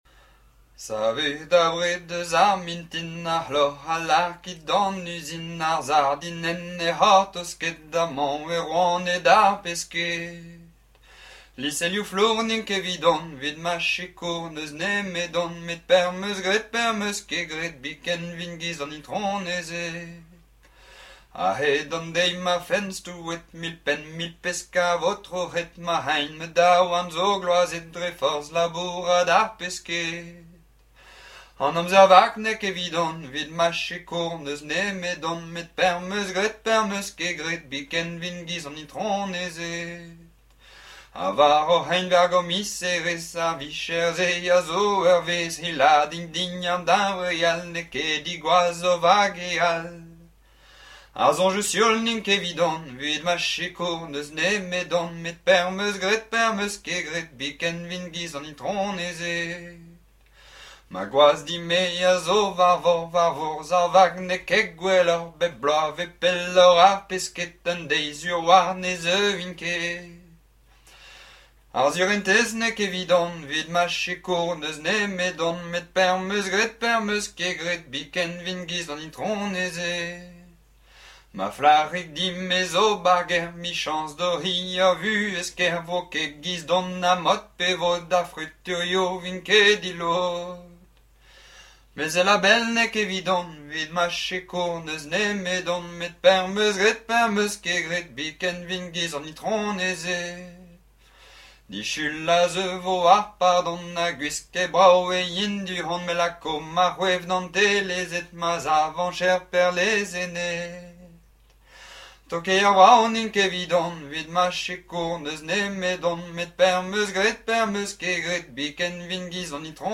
Informateur(s) Ribin ar Sardin (association)
Genre strophique
Pièce musicale inédite